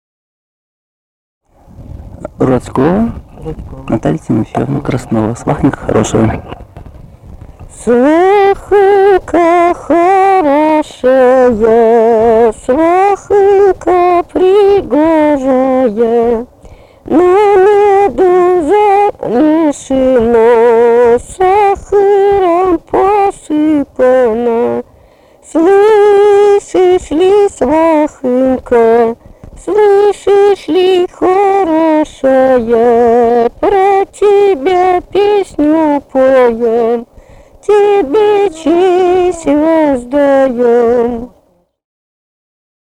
Русские народные песни Владимирской области 16. Свахонька хорошая (свадебная жениху) д. Ротьково Гороховецкого района Владимирской области.